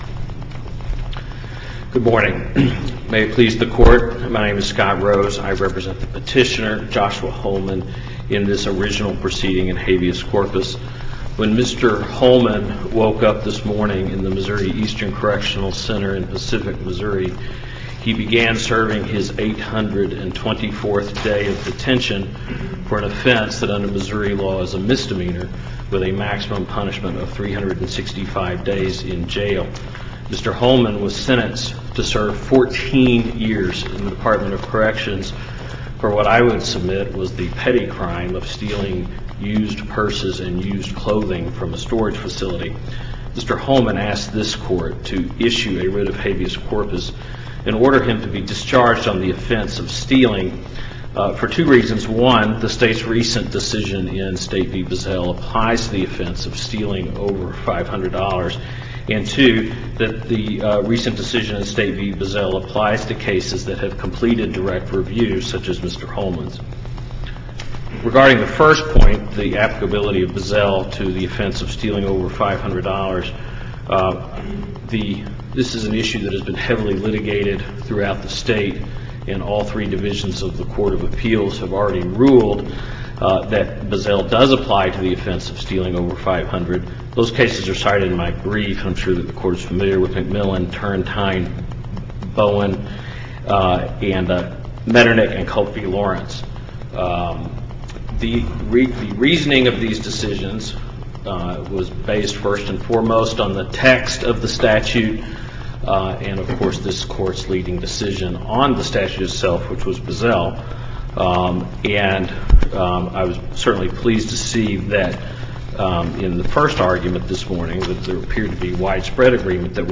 MP3 audio file of arguments in SC96159
Application of a recent case involving felony stealing enhancements to a prior stealing conviction Listen to the oral argument